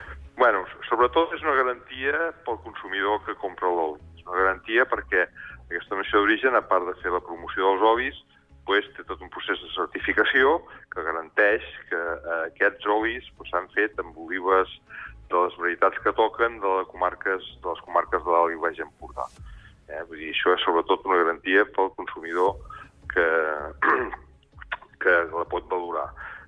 EntrevistesSupermatí